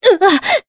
Aselica-Vox_Damage_jp_01.wav